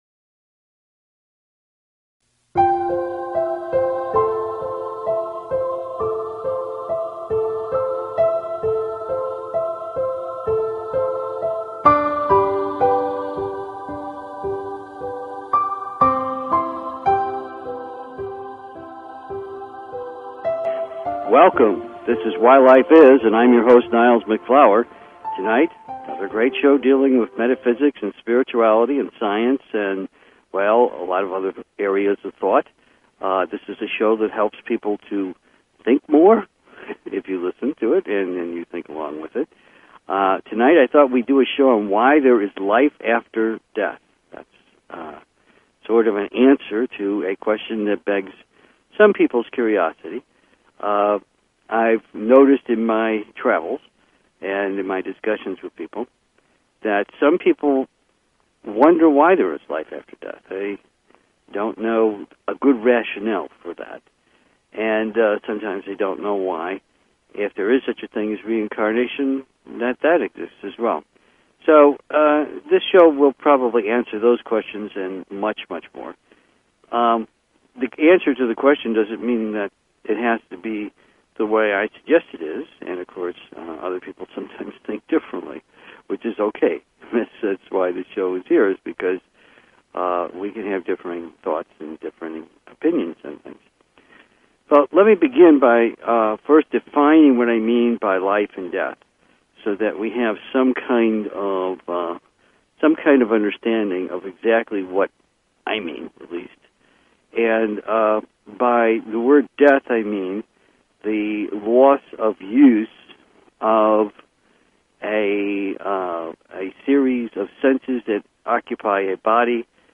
Talk Show Episode, Audio Podcast, Why_Life_Is and Courtesy of BBS Radio on , show guests , about , categorized as